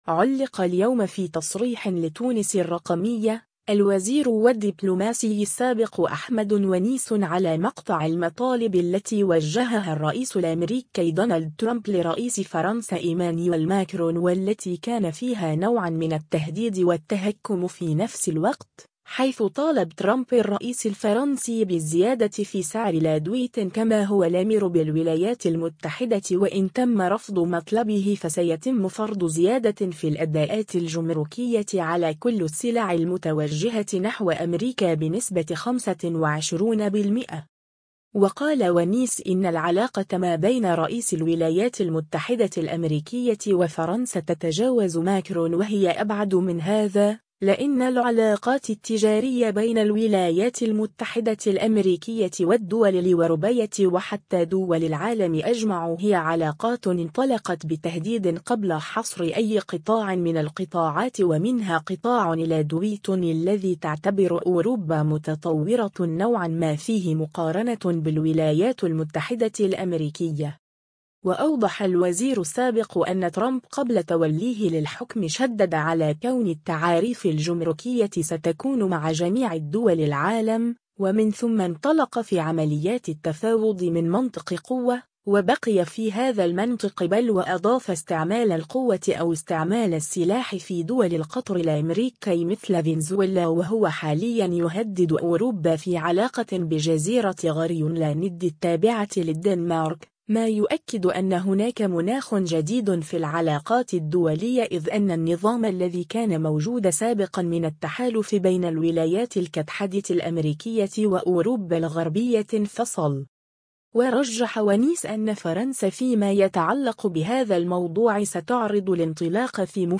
علّق اليوم في تصريح لتونس الرّقمية، الوزير و الدّبلوماسي السابق أحمد ونيس على مقطع المطالب التي وجّهها الرّئيس الامريكي دونالد ترامب لرئيس فرنسا إيمانويل ماكرون و التي كان فيها نوعا من التهديد و التهكّم في نفس الوقت، حيث طالب ترامب الرّئيس الفرنسي بالزيادة في سعر الادوية كما هو الامر بالولايات المتّحدة و ان تمّ رفض مطلبه فسيتمّ فرض زيادة في الأداءات الجمركية على كلّ السلع المتوجهة نحو أمريكا بنسبة 25 %…